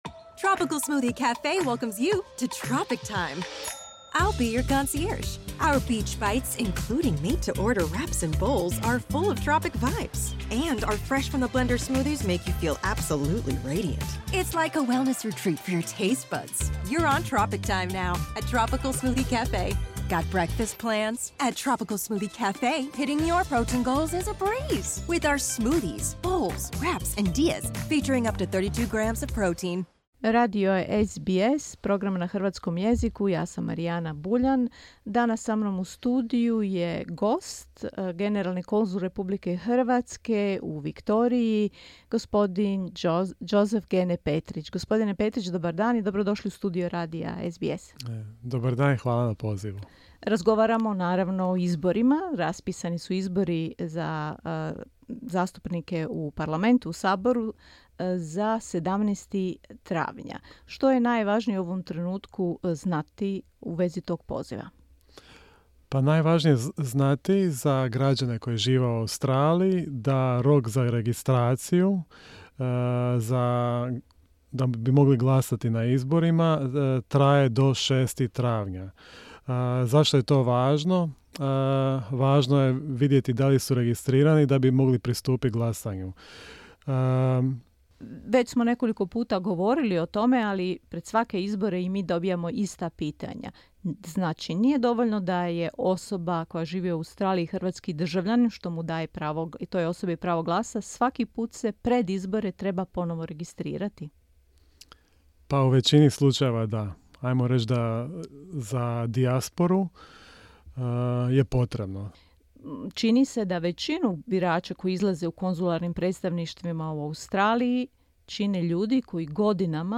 Raspisani su izbori za zastupnike u hrvatskom Saboru. Svi hrvatski državljani, bez obzira gdje žive, imaju pravo glasa. O održavanju izbora u Australiji govori Joseph Gene Petrich, generalni konzul RH u Melbourneu.